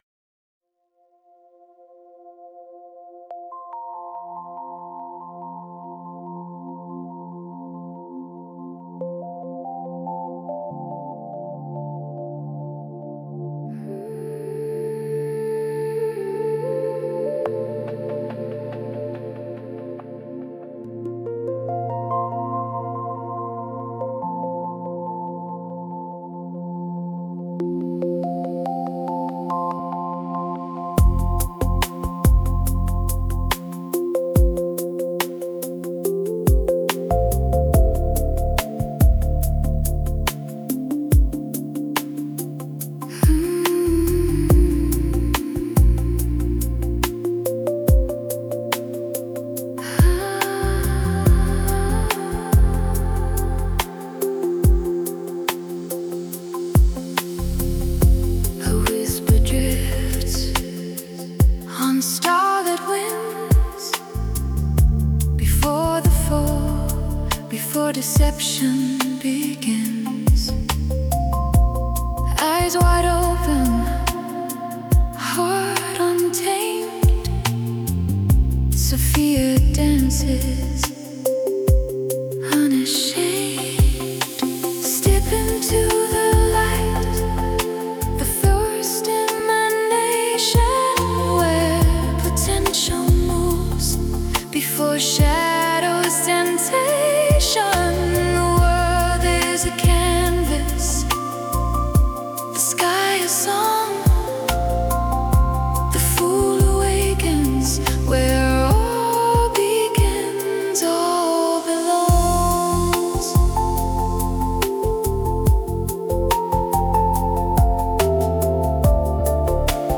Full album with 8 Songs produced in 432Hz